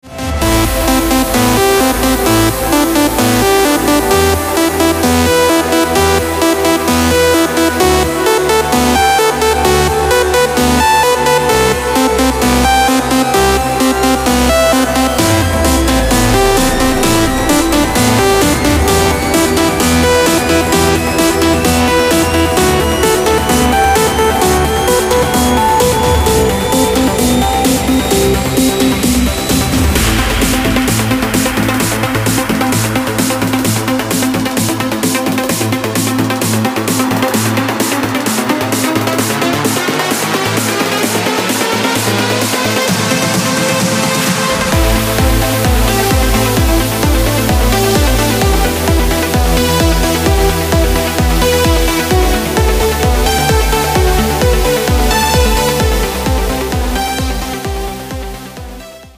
• Качество: 256, Stereo
Trance
electro